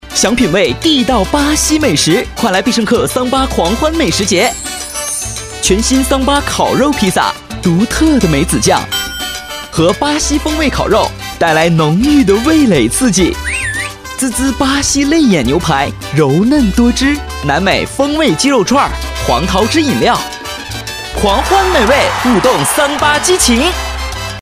年轻时尚 品牌广告